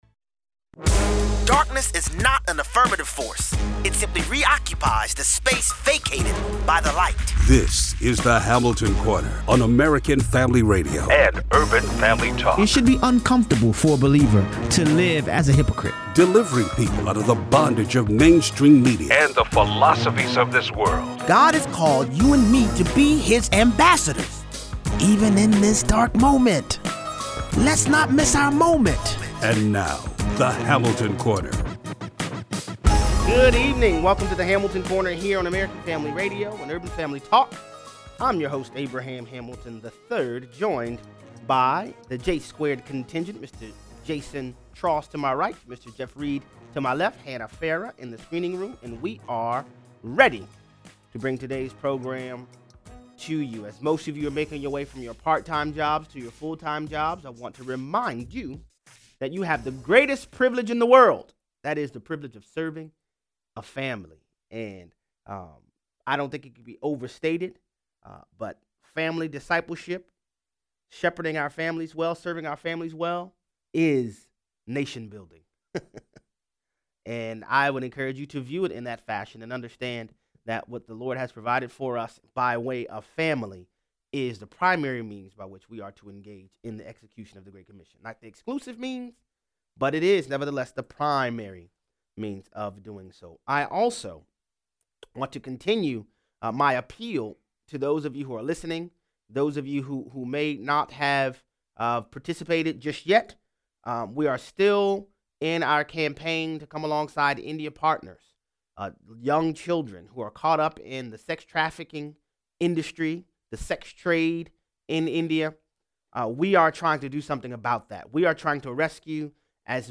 Is there a link here? 0:38 - 0:55: New emails confirm the FBI tried work a deal with the State Department to minimize Hillary Clinton email scandal. Callers weigh in.